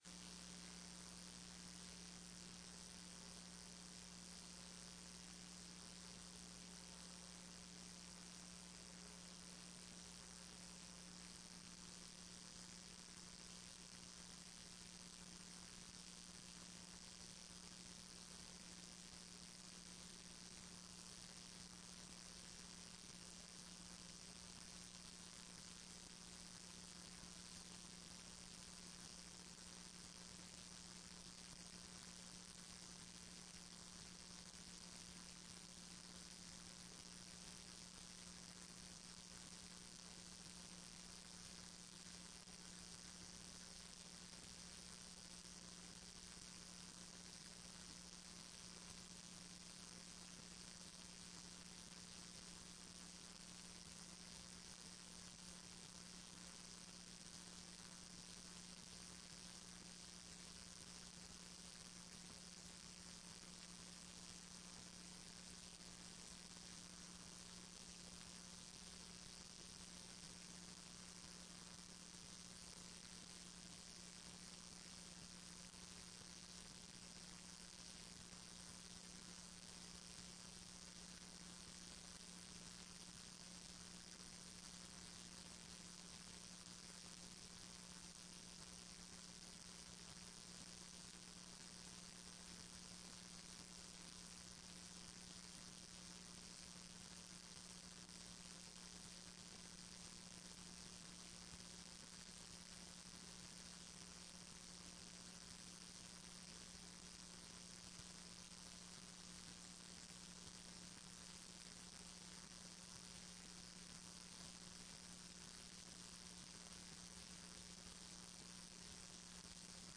TRE-ES - Áudio da sessão 21.10.14